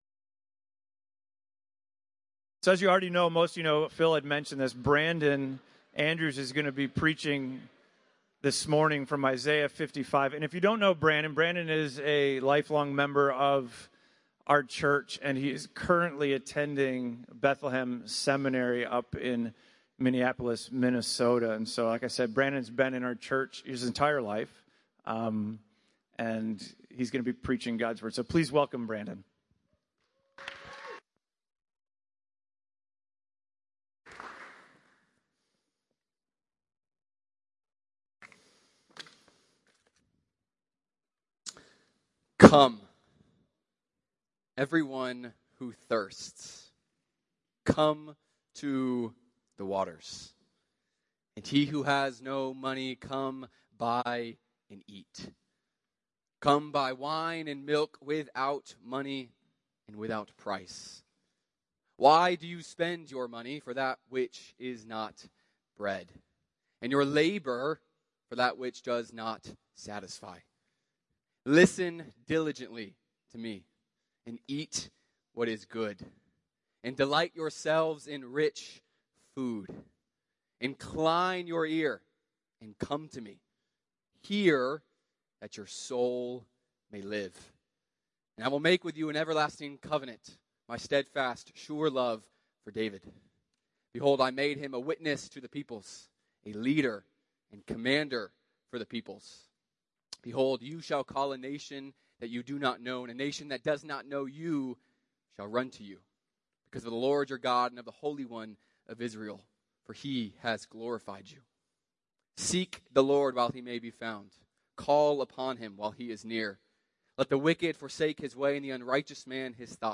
Sermons from the pulpit of Sovereign Grace Church in Woodstock, GA.